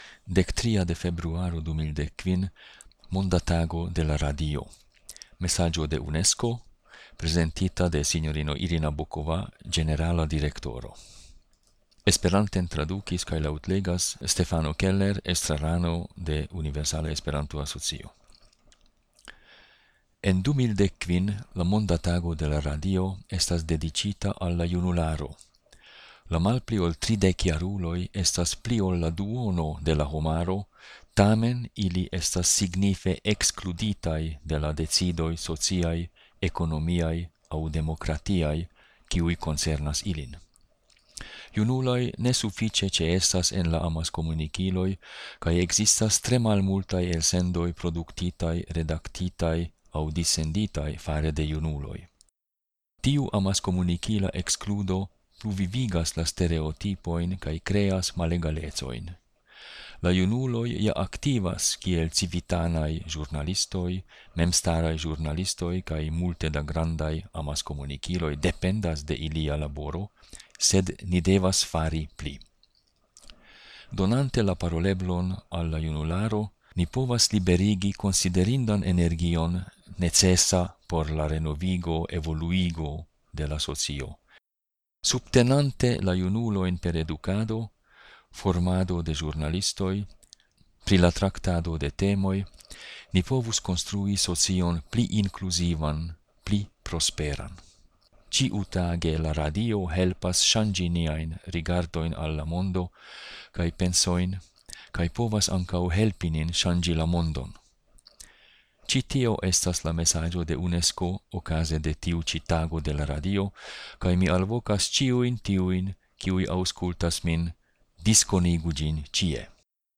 laŭtlegas en esperanto la mesaĝon de Unesko/Irina Bokova, okaze de la Monda Tago de la Radio, 13-a de februaro 2015.